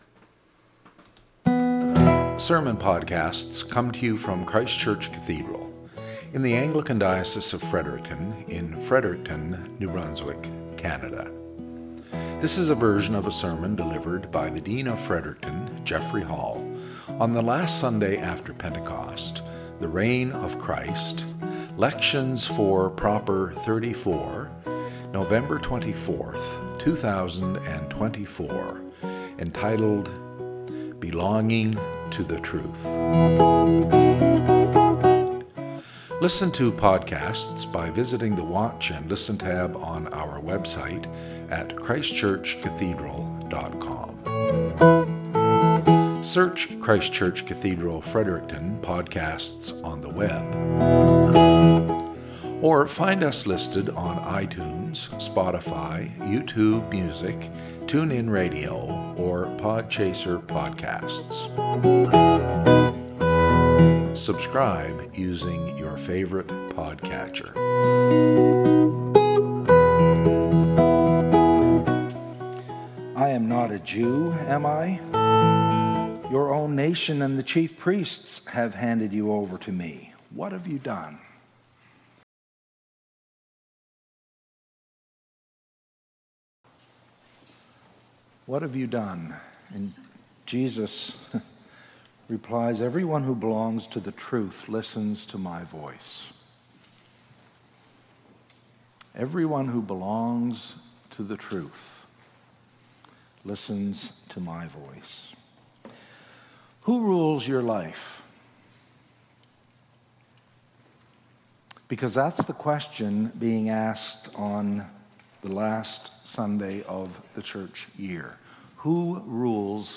SERMON - "Belonging to the Truth"